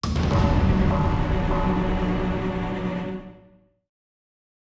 Warning Sound.wav